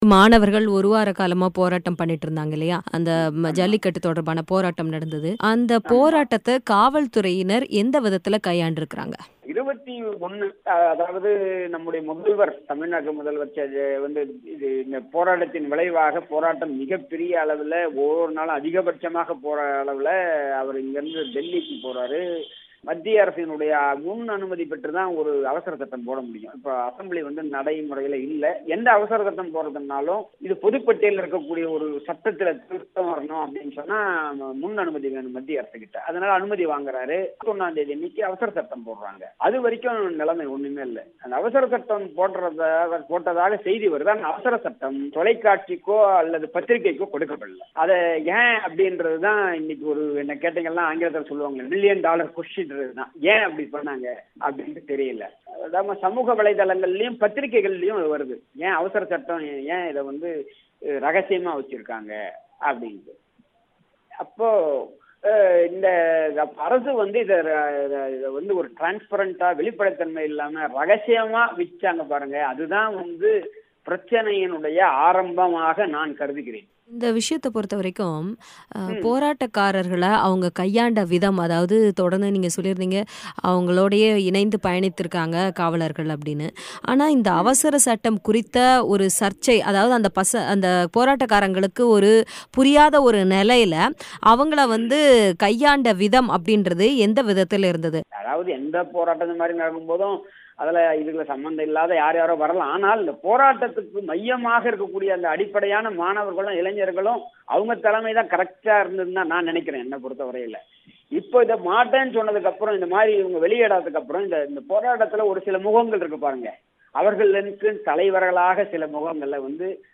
சென்னை போராட்டத்தில் வன்முறை: முன்னாள் நீதிபதி ஹரிபரந்தாமன் பேட்டி